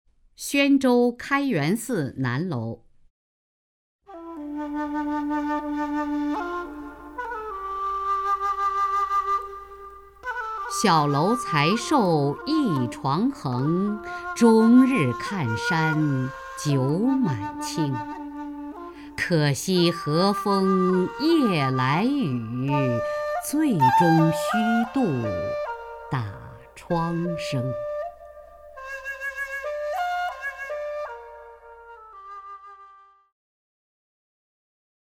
雅坤朗诵：《宣州开元寺南楼》(（唐）杜牧) (右击另存下载) 小楼才受一床横， 终日看山酒满倾。
名家朗诵欣赏